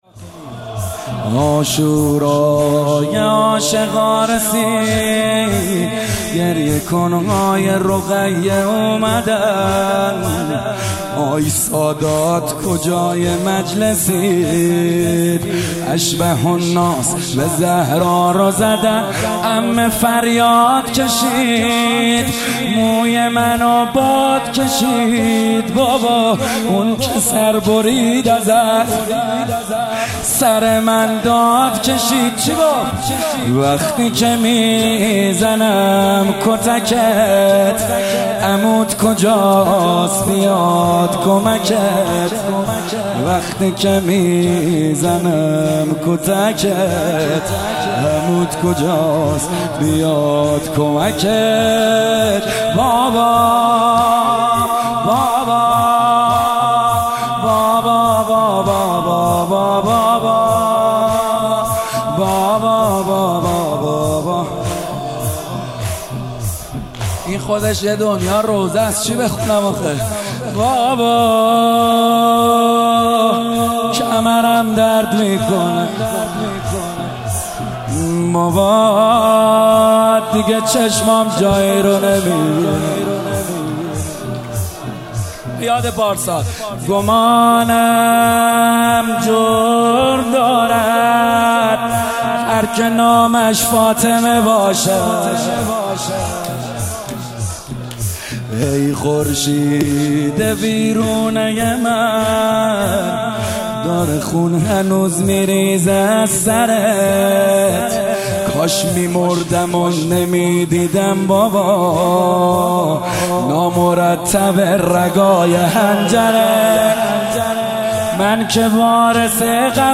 محرم98 - شب سوم - زمینه - گریه کن های رقیه اومدن
مهدیه امام حسن مجتبی(ع)